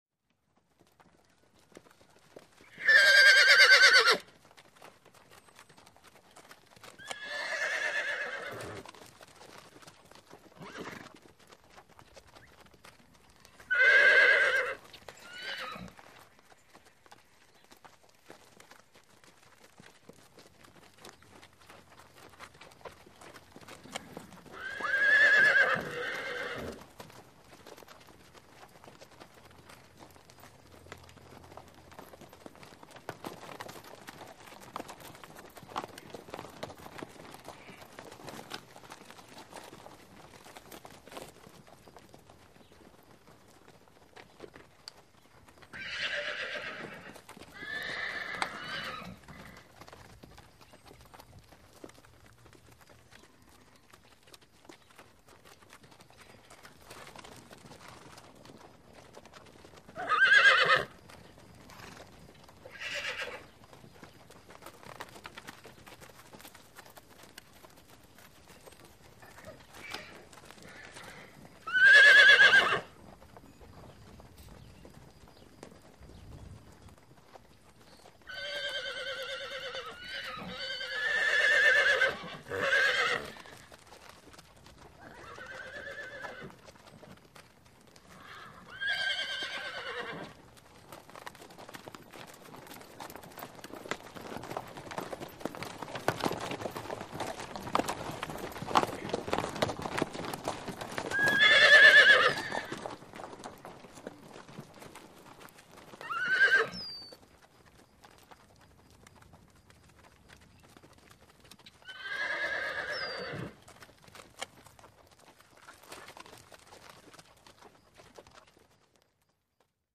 Horse Vocals | Sneak On The Lot
A Small Group Of Horses Whinny And Trot And Are Joined By Several More Trotting And Whinnying.